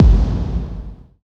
Bass Boom.wav